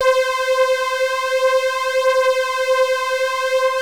PULZEFLANG.8.wav